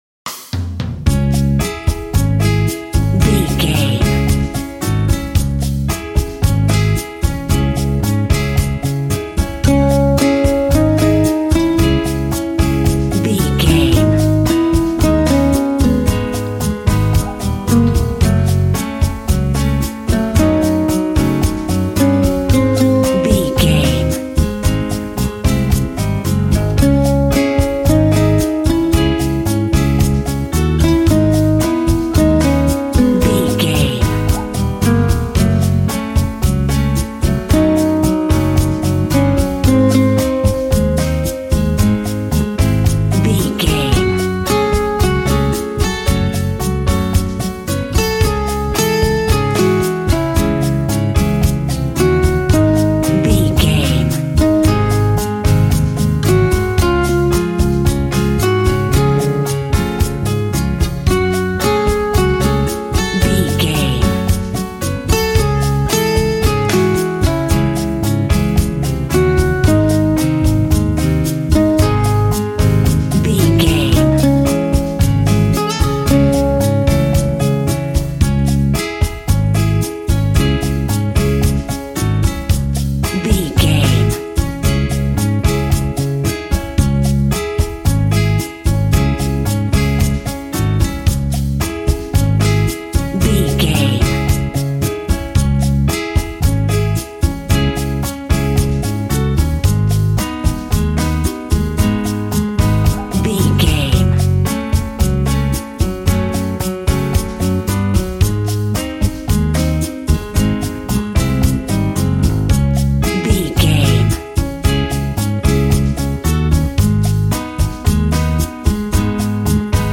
Aeolian/Minor
D♭
funky
energetic
romantic
percussion
electric guitar
acoustic guitar